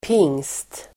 Ladda ner uttalet
pingst substantiv, Whitsun Uttal: [ping:st] Böjningar: pingsten Definition: kristen högtid som infaller sju veckor efter påsk Sammansättningar: pingst|dag (Whitsunday), pingst|bröllop (a wedding at Whitsun)